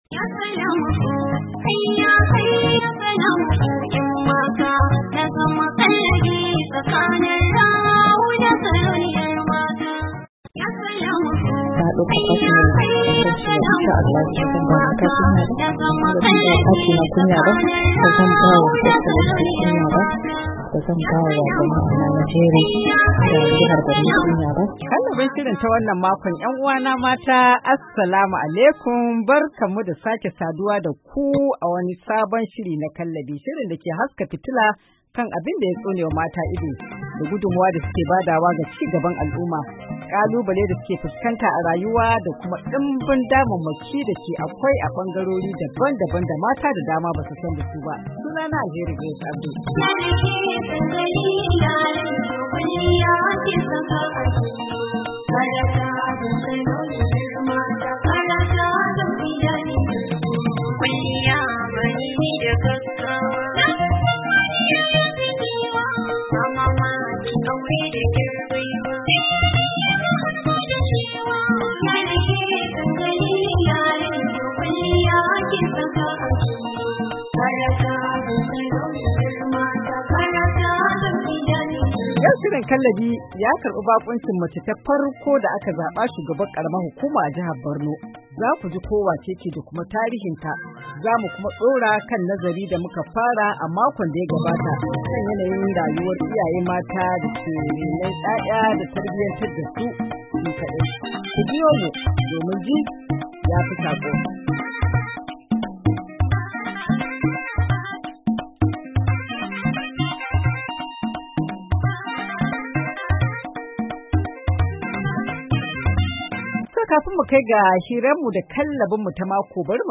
Shirin na wannan makon cigaban hira da Hajiya Inna Chiroma da ta yi tarihi a matsayin macen farko da aka zaba Shugabar karamar Hukuma a jihar Borno, shirin kallabi ya kuma haska fitila kan masababin barwa mata nawayar kula da ‘ya’yansu ko da kuwa suna tare da iyayen maza.